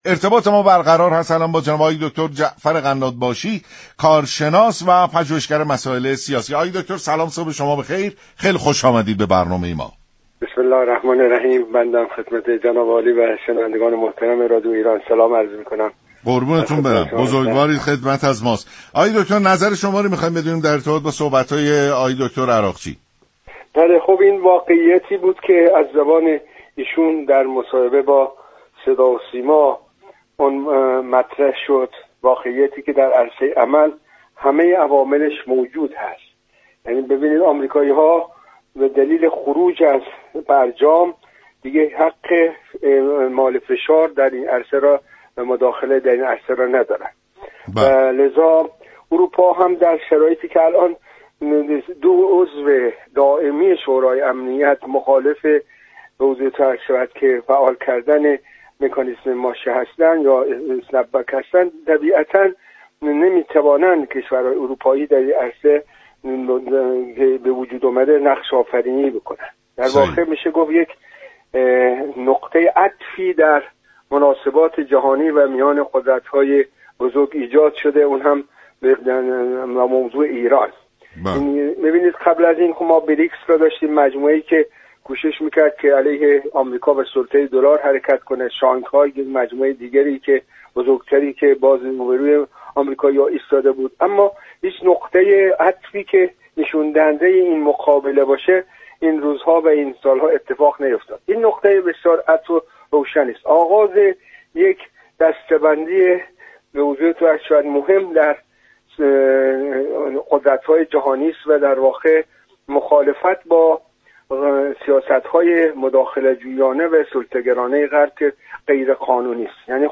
برنامه سلام‌صبح‌بخیر شنبه تا پنج‌شنبه هر هفته ساعت 6:15 از رادیو ایران پخش می‌شود.